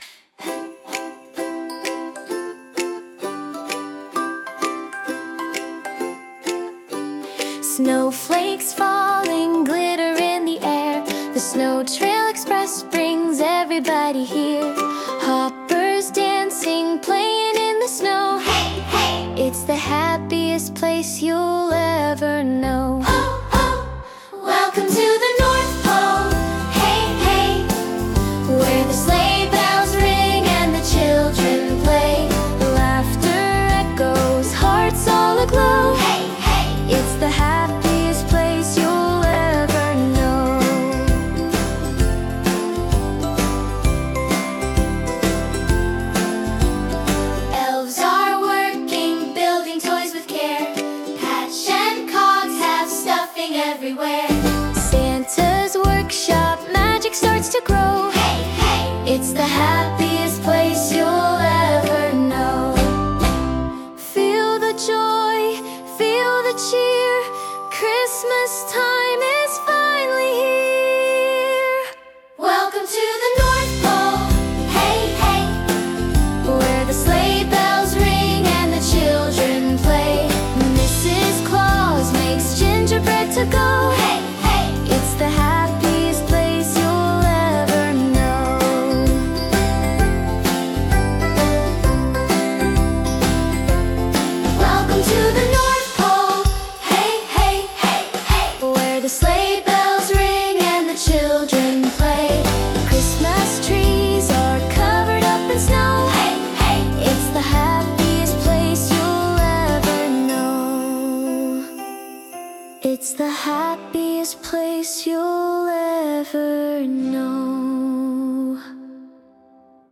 get ready for an instant festive earworm!